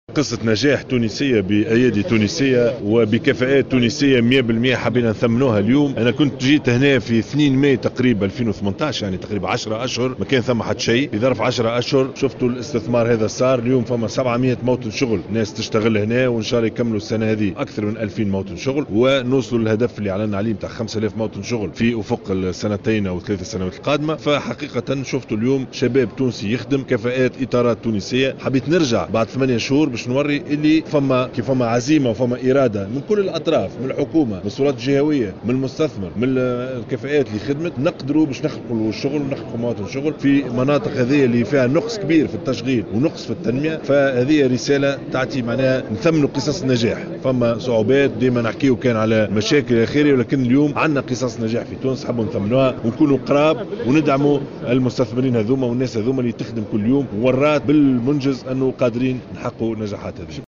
Il y a une volonté de toutes les parties prenantes pour créer de l’emploi dans ces régions où il y a un manque d’employabilité et de développement», a affirmé le chef du gouvernement dans une déclaration à Jawhara FM.